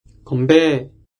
韓国語で「건배」は、漢字の「乾杯」をそのまま韓国語読みしたもので、「コンベ」と発音します。
発音と読み方
韓国人講師の音声を繰り返して聞きながら発音を覚えましょう。
건배 [コンベ]